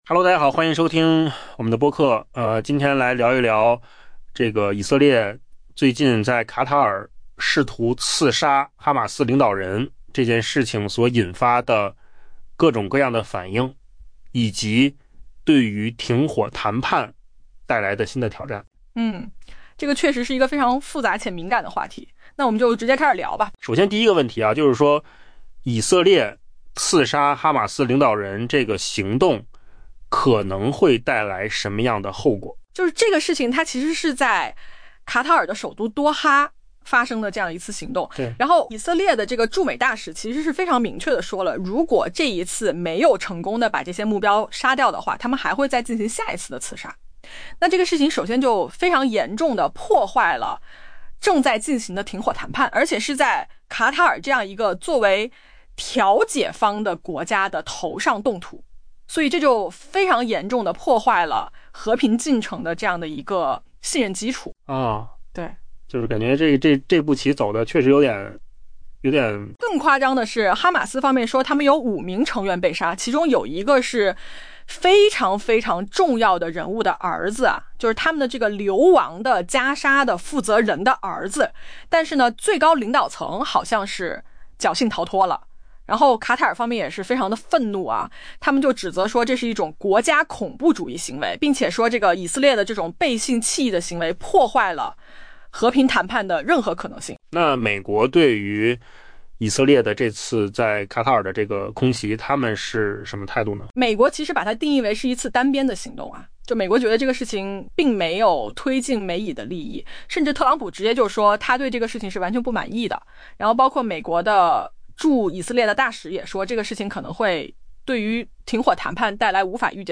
AI 播客：换个方式听新闻 下载 mp3 音频由扣子空间生成 以色列驻美国大使表示， 如果以色列周二在对卡塔尔的袭击中未能杀死哈马斯领导人，那么下一次将会成功。